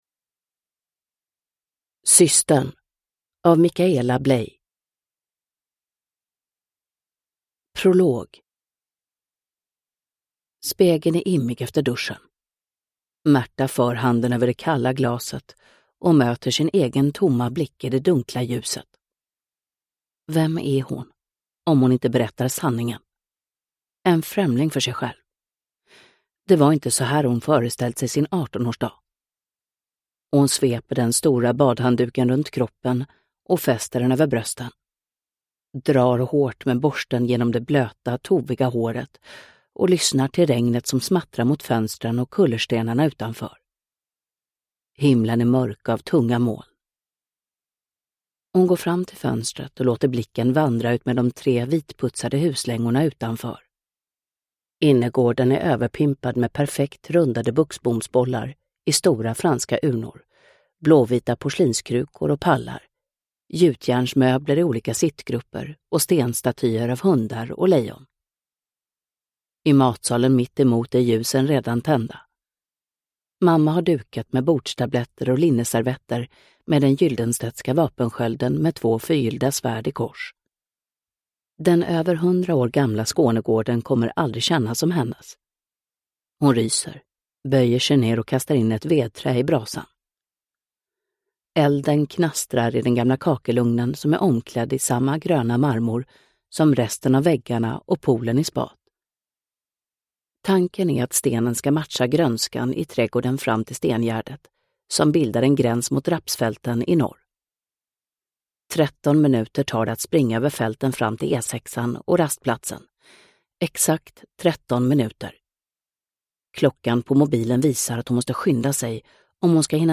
Systern – Ljudbok – Laddas ner